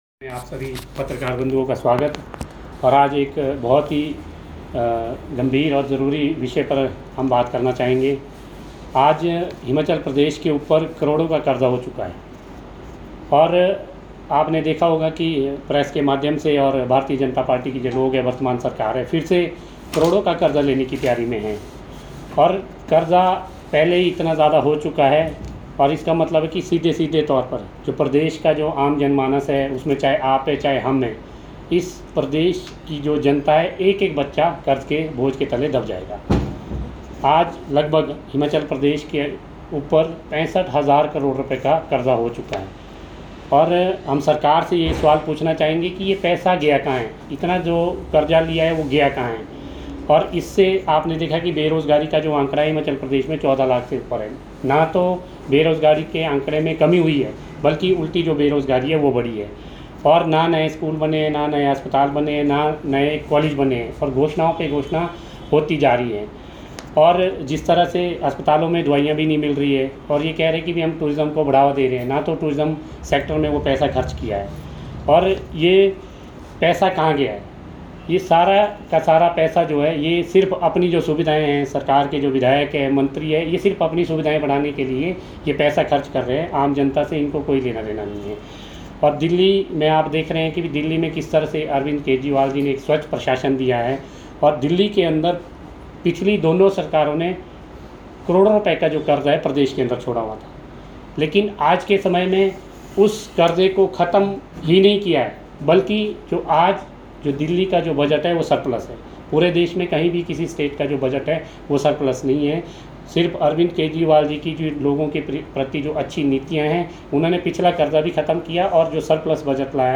पांवटा साहिब में पत्रकारों को संबोधित करते हुए